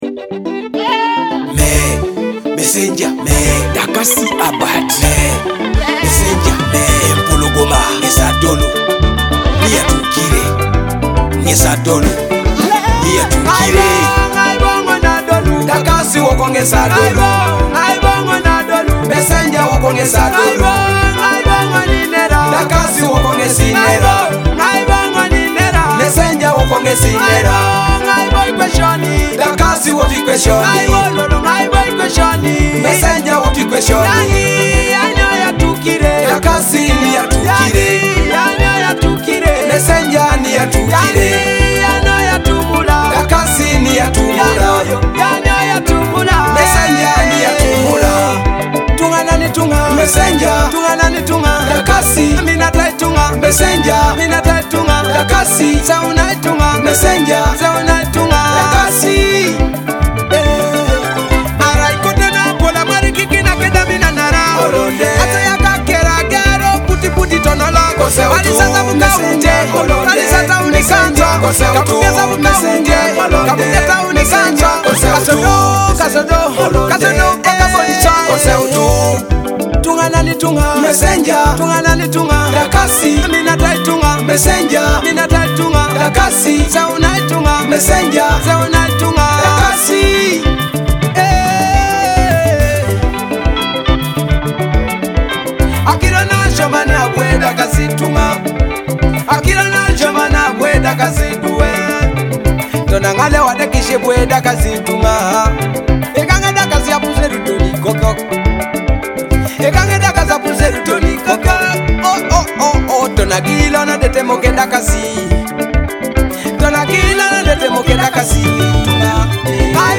a powerful Teso music track that tells the story of life
With deep lyrical content and traditional rhythms